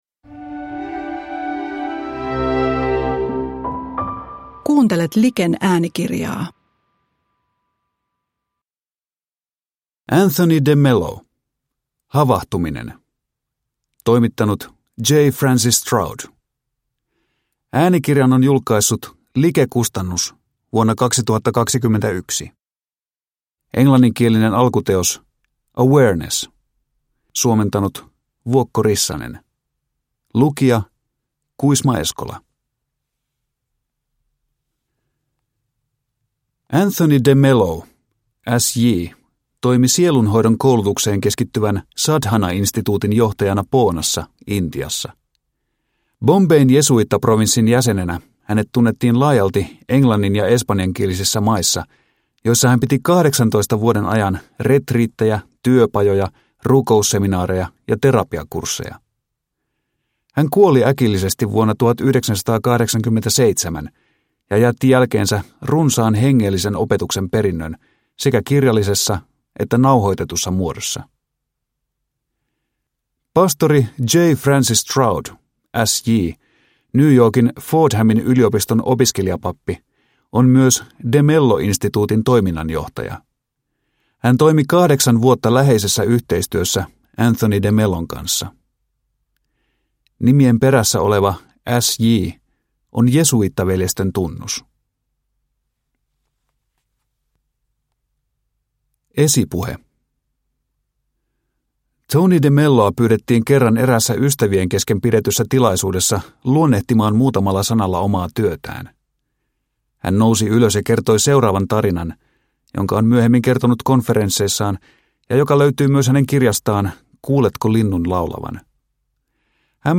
Havahtuminen – Ljudbok – Laddas ner